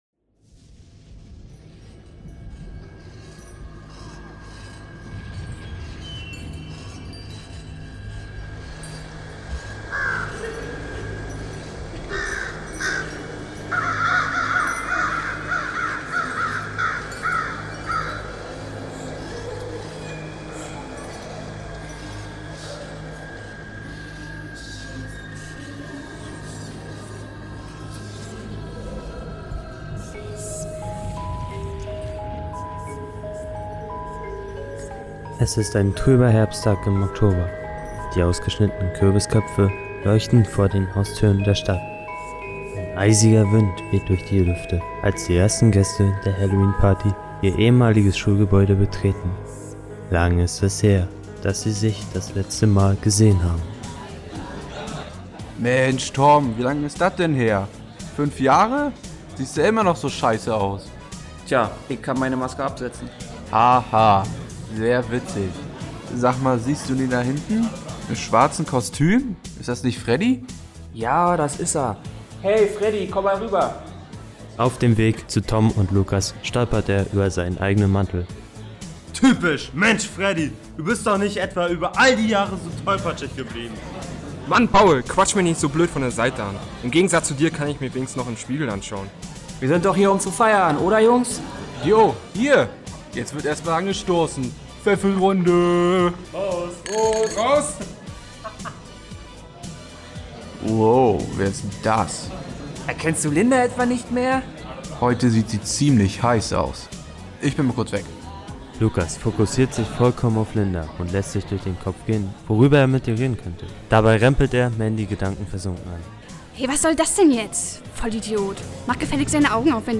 Genre festlegen, Story überlegen, Charaktere festlegen, Texte schreiben, Manuskript erstellen, Sprecher zuordnen, Aufnahmen machen, Aufnahmen schneiden, Hintergrundmusik und Geräusche heraussuchen, stundenlanges Zusammenmixen von Aufnahmen, Geräuschen und Hintergrundmusik, Hörspiel-Titel ausdenken, CD-Hüllen fertigstellen, Cover jeweils für CD und Kassette gestalten, CD’s brennen, Aufnahmen auf die Kassetten spielen, Intro-Text für das Kassetten-Cover finden.